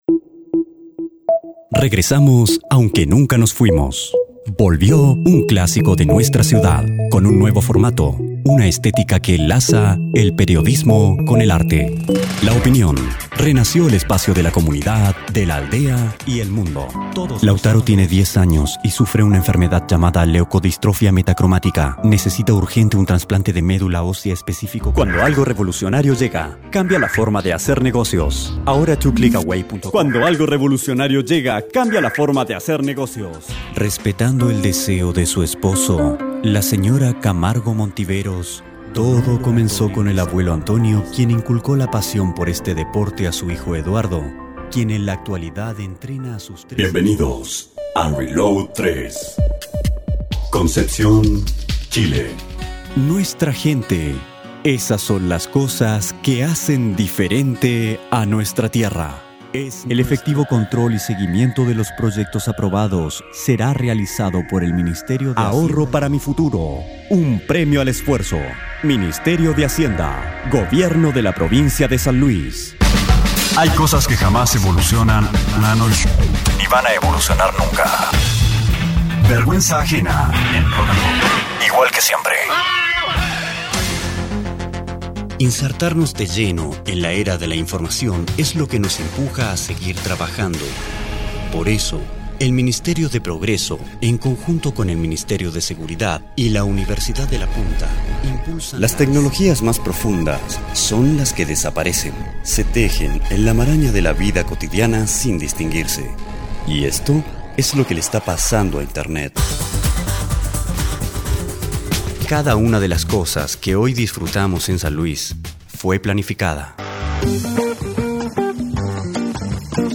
Locutor Voz para Institucionales, comerciales, radio, tv, cine.
Sprechprobe: Werbung (Muttersprache):